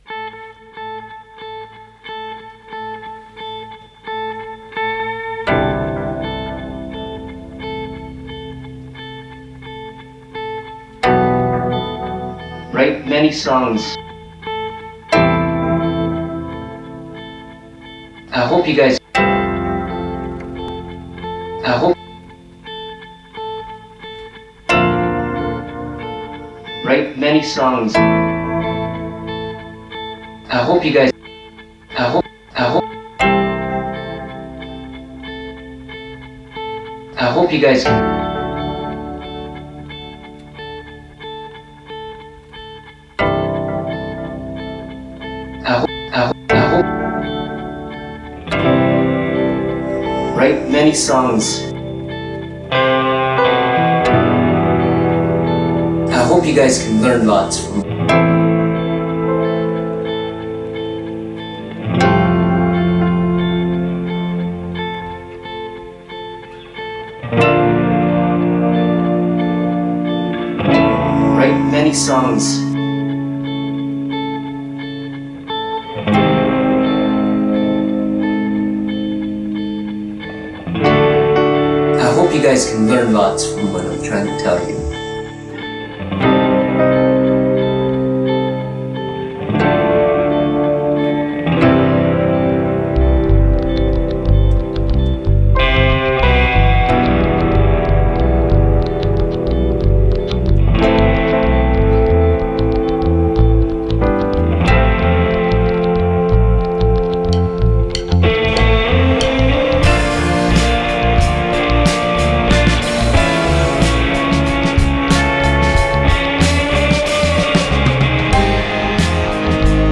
recorded live
lead vocalist and drummer